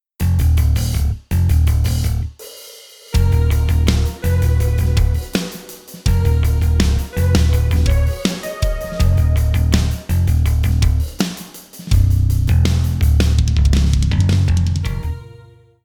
7) Бас гитара
7) Modo Bass + BooBass, полностью устраивает.
Бас тест.mp3